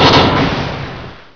door_close_1.ogg